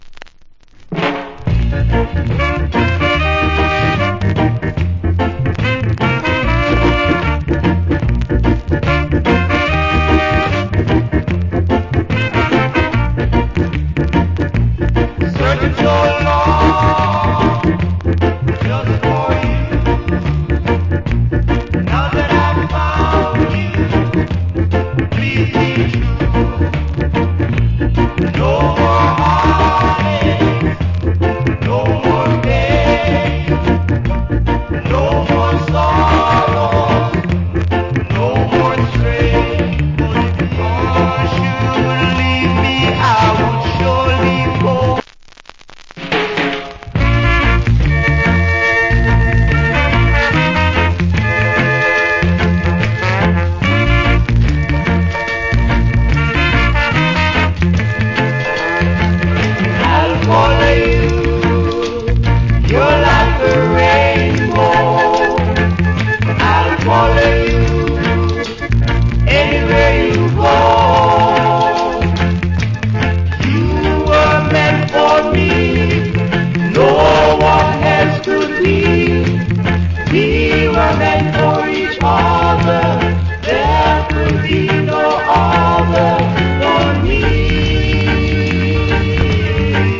Nice Early Reggae Vocal. / Good Rock Steady Vocal.